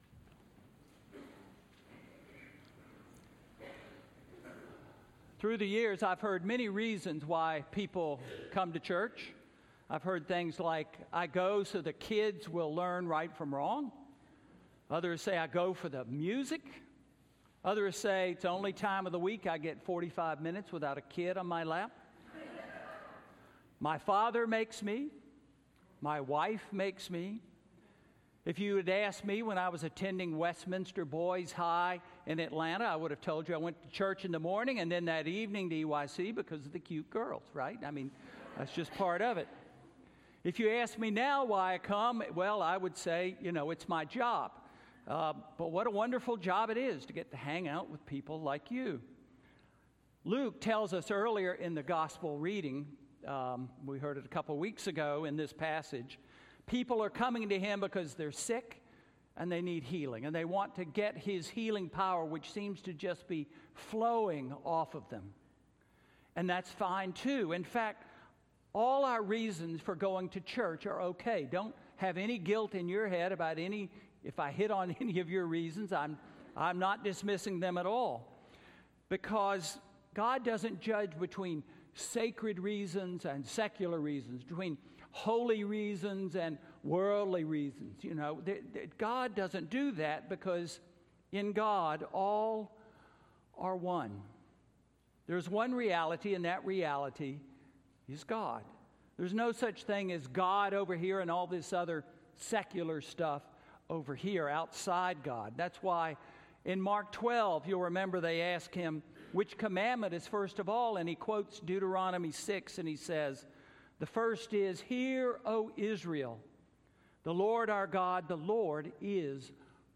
Sermon–Love Who?!?–February 24, 2019